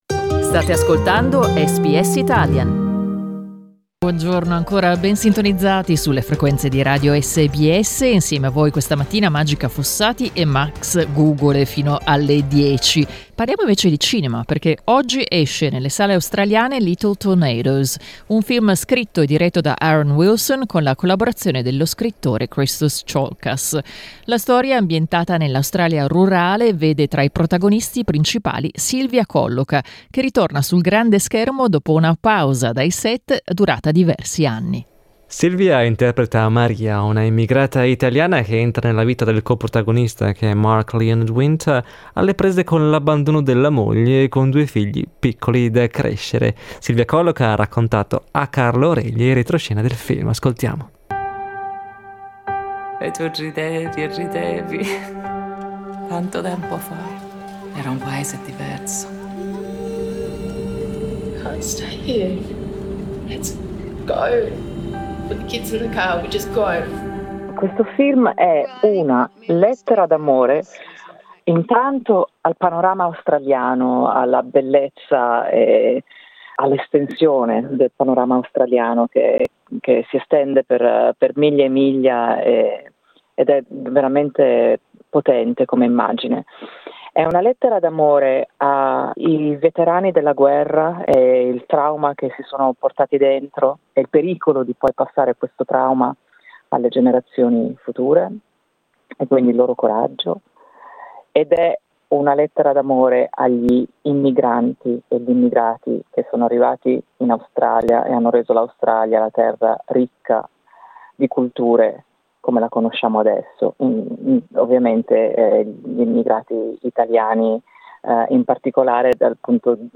Ascolta l'intervista a Silvia Colloca, che ha raccontato a SBS Italian i retroscena del film.